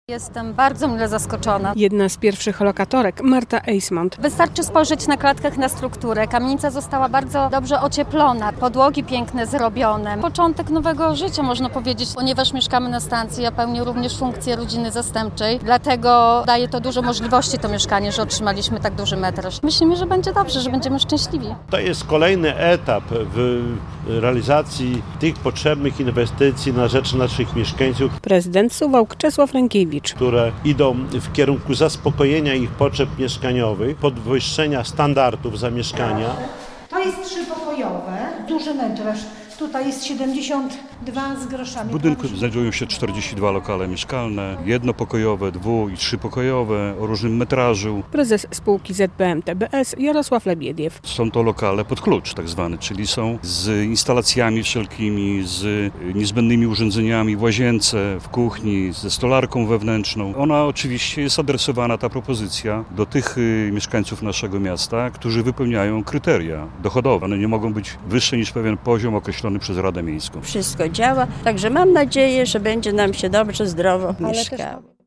relacja
Jak dodaje prezydent miasta Czesław Renkiewicz - potrzeby mieszkaniowe suwalczan są duże, a ta kamienica spełnia przynajmniej część zapotrzebowania na mieszkania.